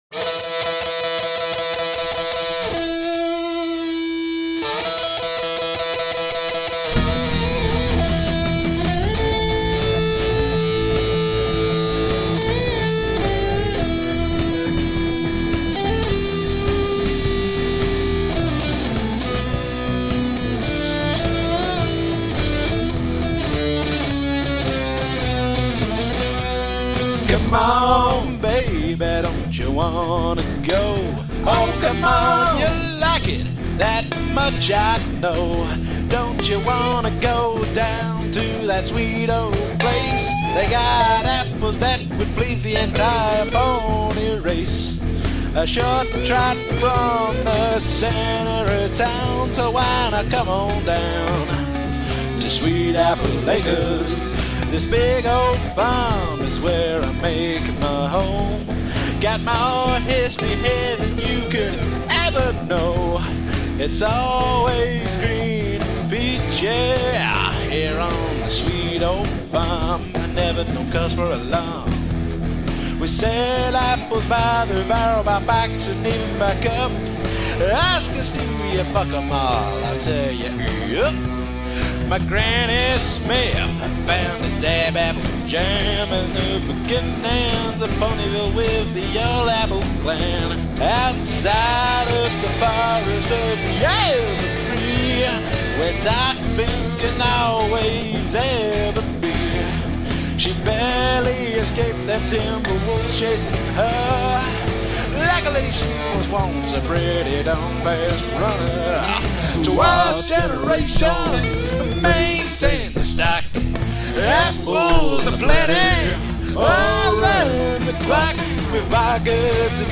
Guitars + Bass
Drums
Hot Plex with the lead having a Skreamer attached to it.